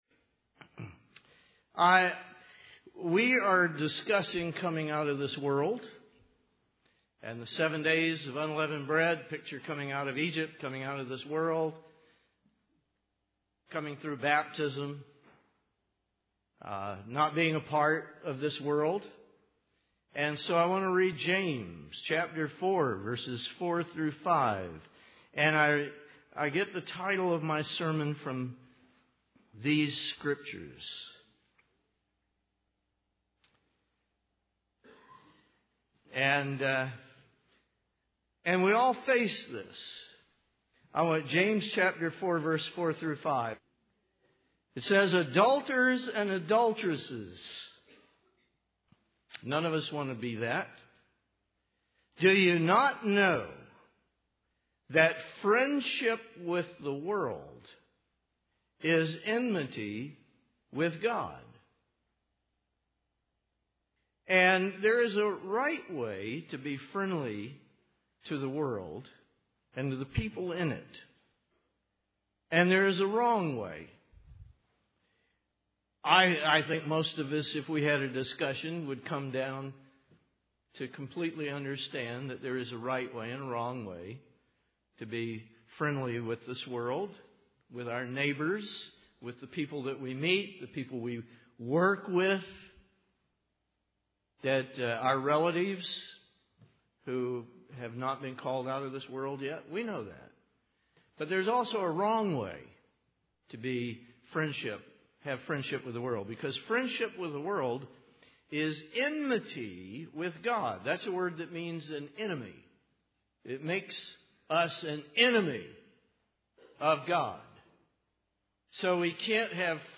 Related reading; Tools for Spiritual Growth UCG Sermon Studying the bible?